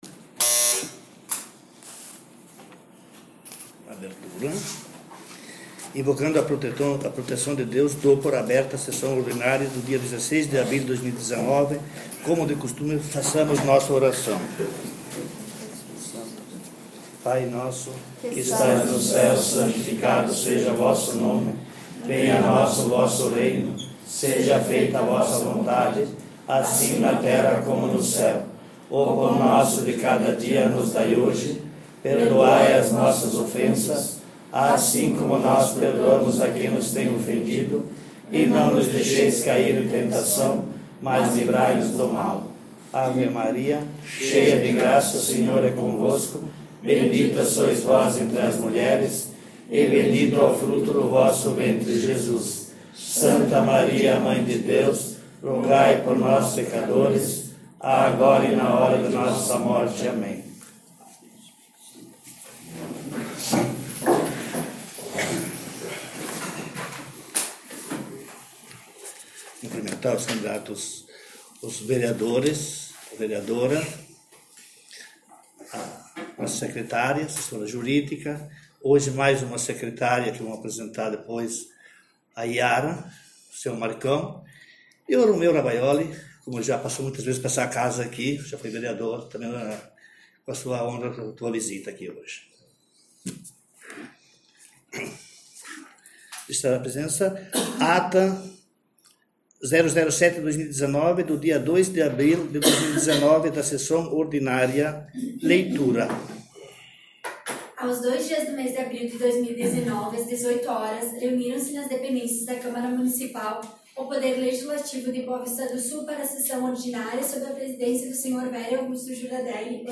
Sessão Ordinária dia 16/04 — Câmara Municipal de Boa Vista do Sul